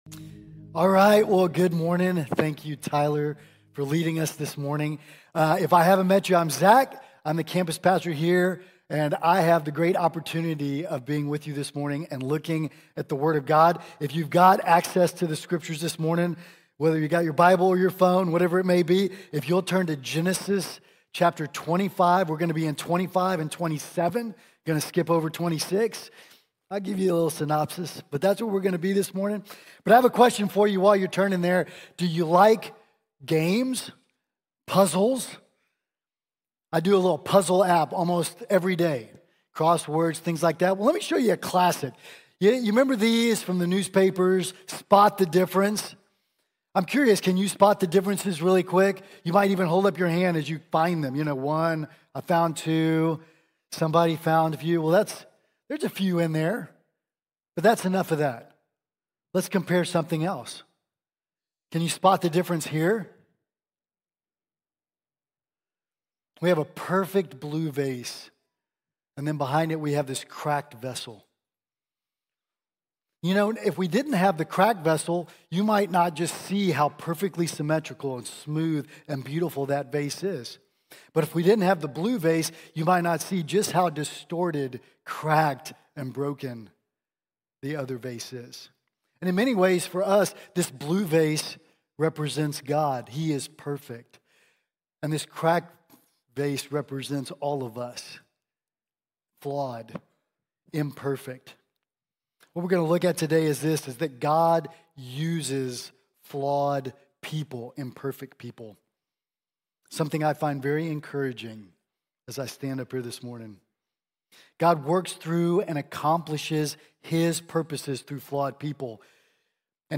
God Works Through Imperfect People | Sermon | Grace Bible Church